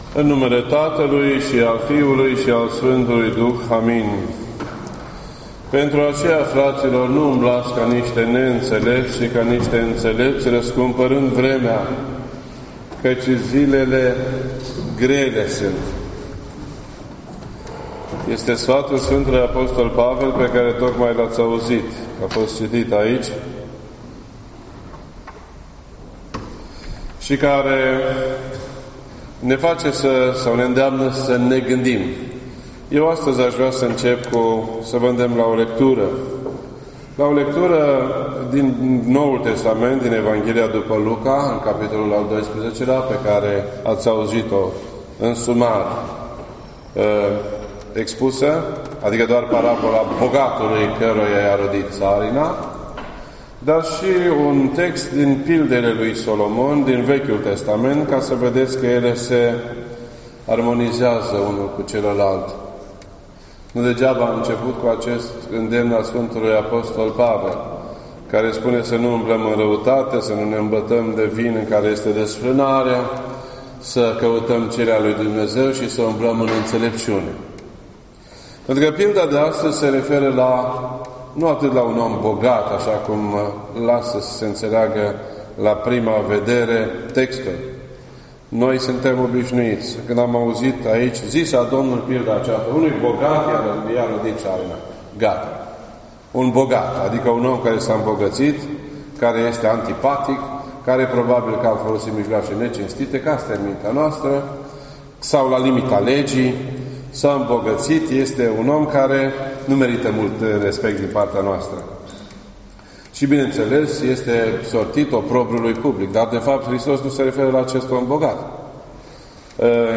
This entry was posted on Sunday, November 17th, 2019 at 4:27 PM and is filed under Predici ortodoxe in format audio.